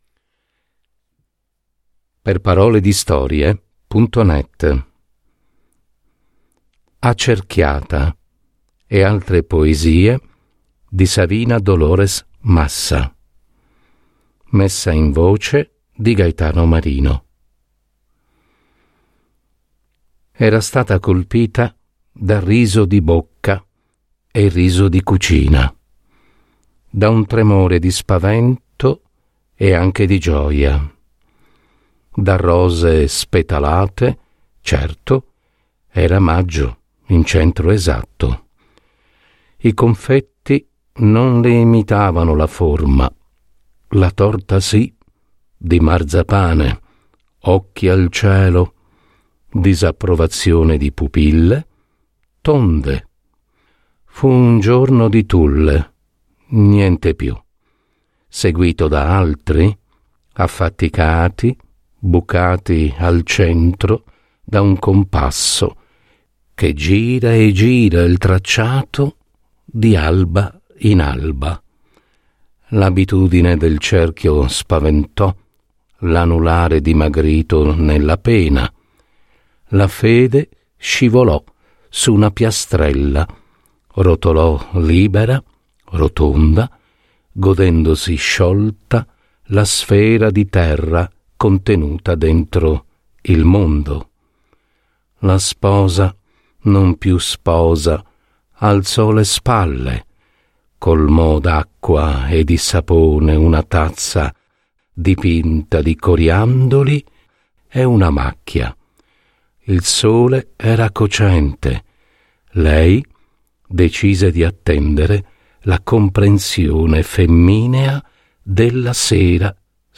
Messa in voce